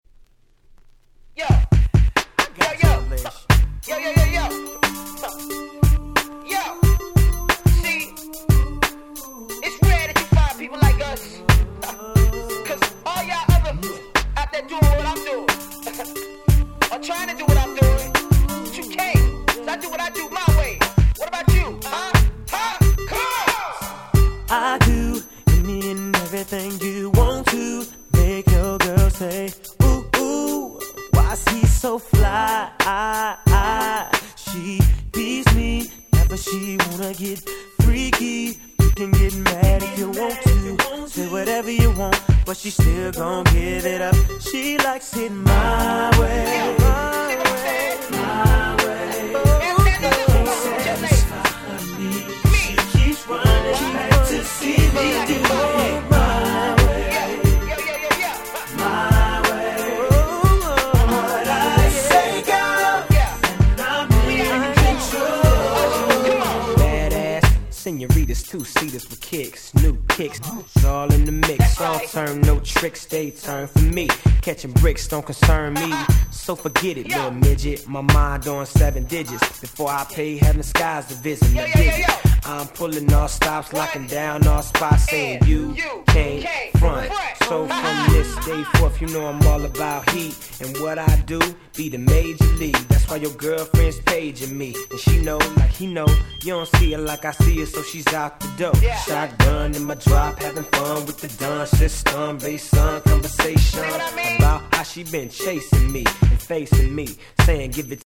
98' Super Hit R&B !!